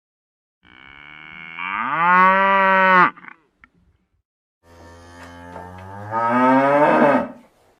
moo.mp3